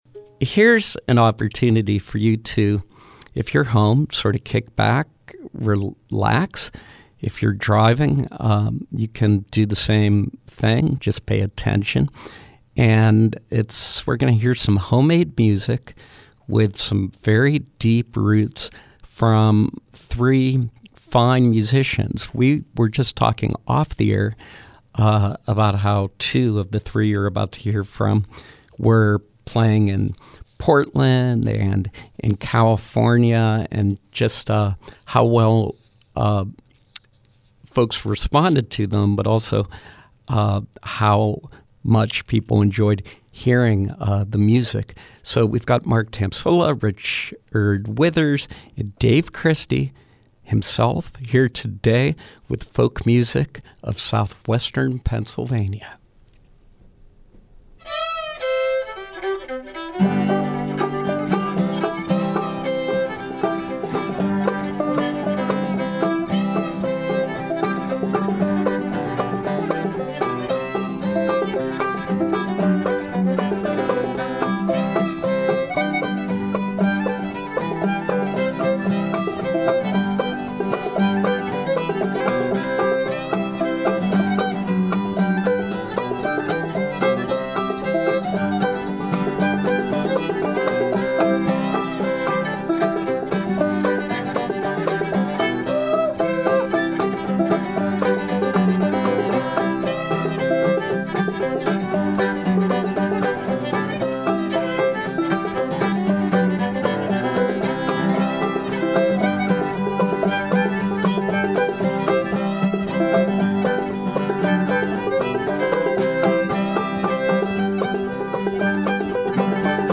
Live Performance: Old Time Music of Southwestern PA